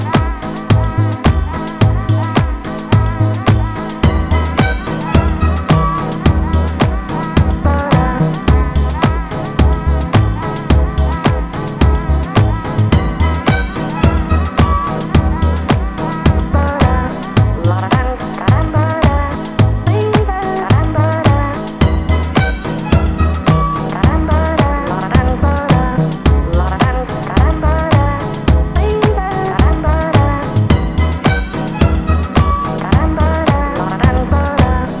Жанр: Рок / Альтернатива / Электроника